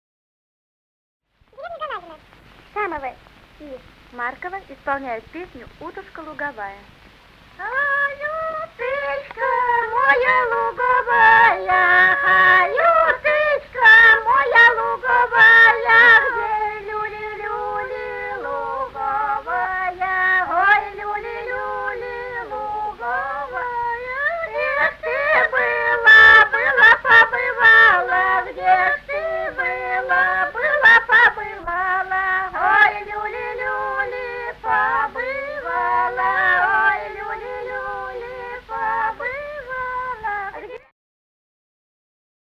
Русские народные песни Владимирской области [[Описание файла::10. Ай, уточка моя луговая (хороводная) д. Галанино Судогодского района Владимирской области.